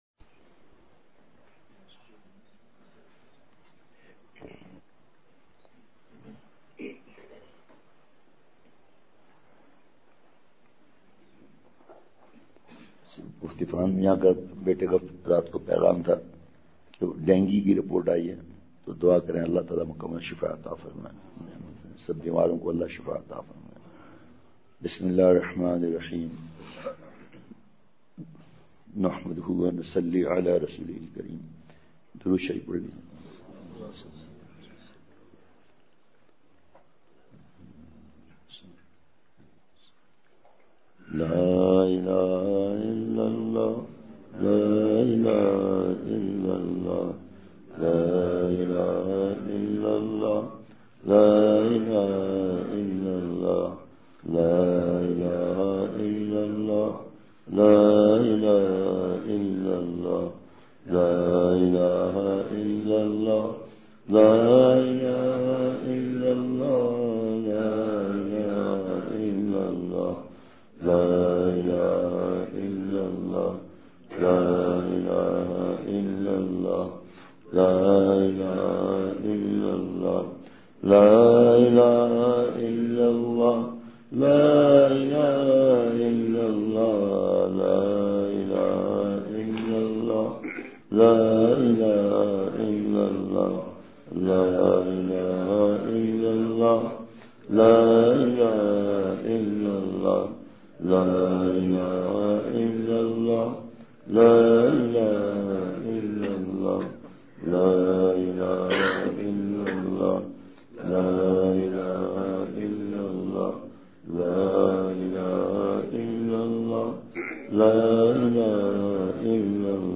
اصلاحی مجلس کی جھلکیاں
بمقام: جامع مسجد زکریا پشین بعد از فجر